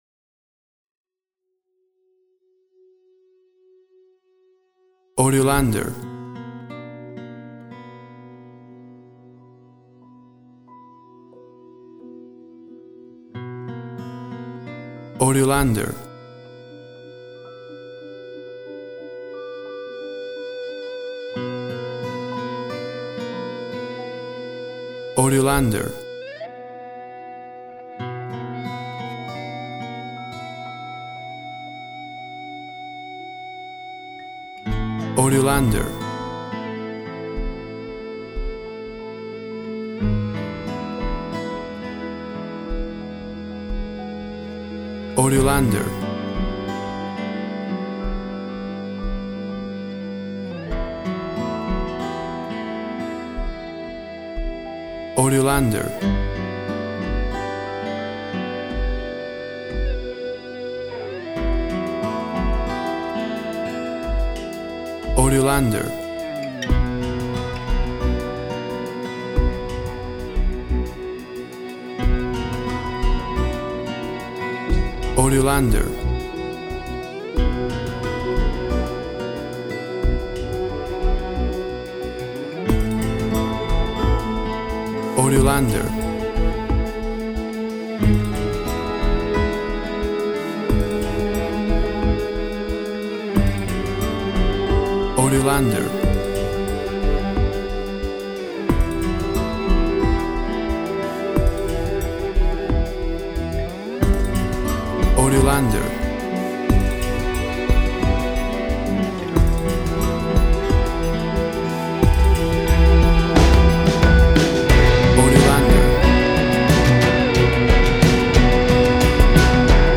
WAV Sample Rate 16-Bit Stereo, 44.1 kHz
Tempo (BPM) 90